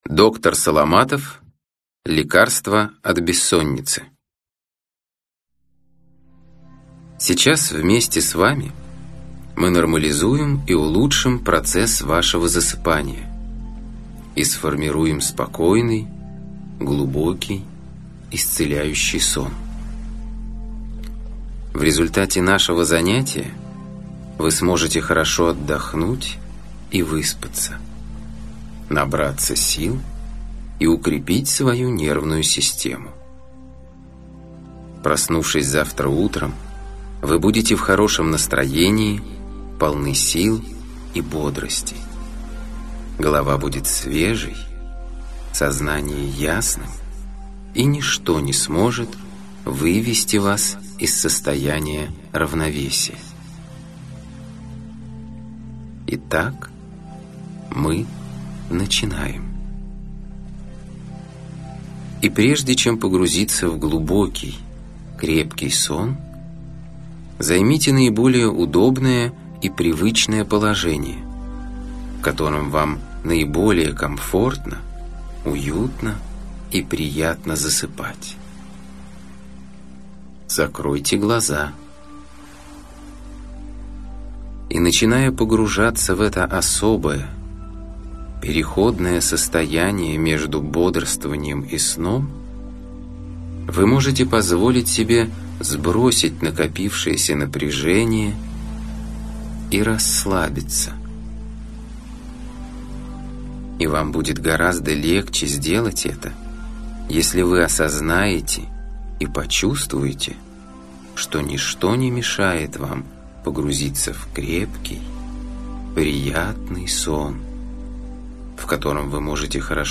Аудиокнига Лекарство от бессонницы | Библиотека аудиокниг